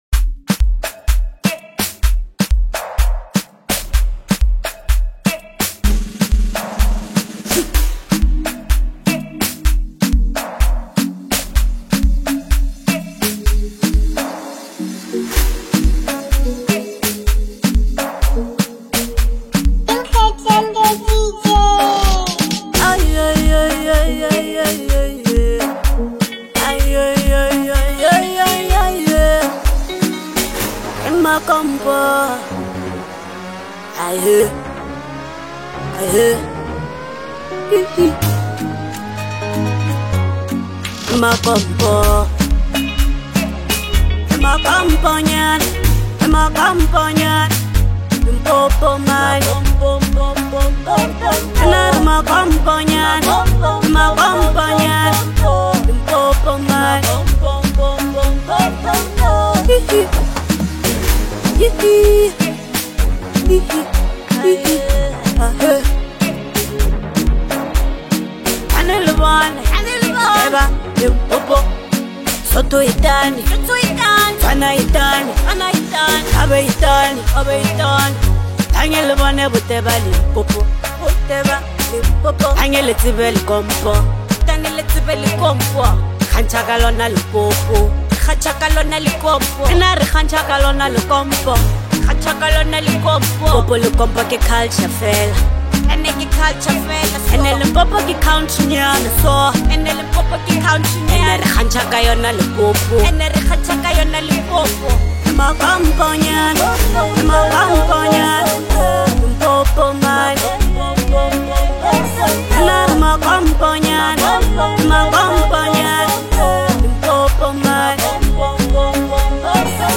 a fearless anthem of place,pride and power
Where to Experience More Afro-House Fire